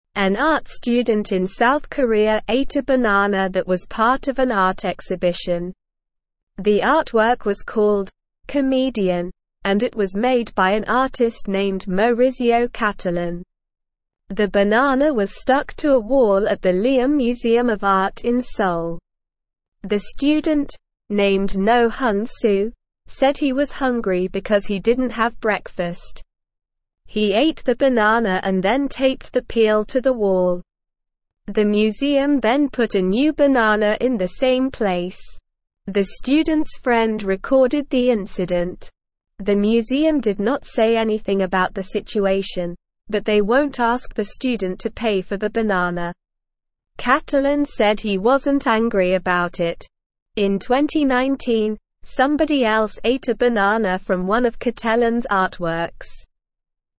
Slow
English-Newsroom-Beginner-SLOW-Reading-Student-Eats-Museum-Banana-Artwork.mp3